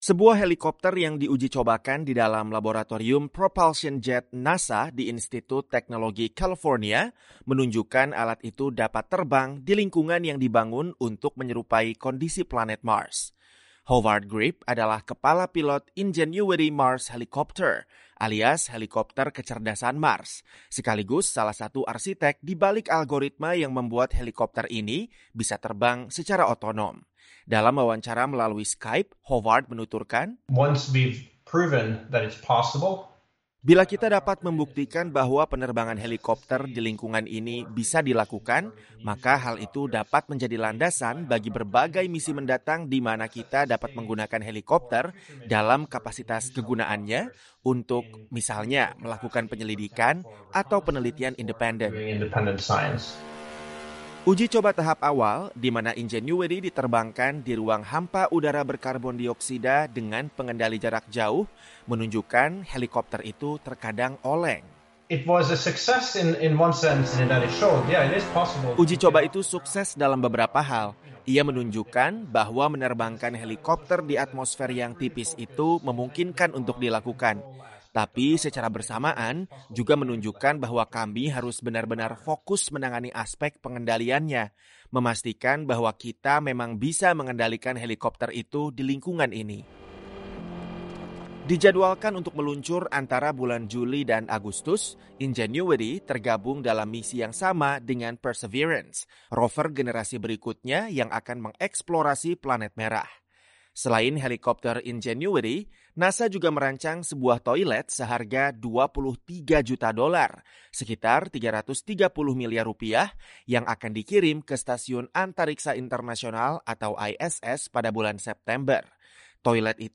Bagian dari misi itu adalah melakukan penerbangan pertama di Mars. Selengkapnya, simak laporan berikut ini.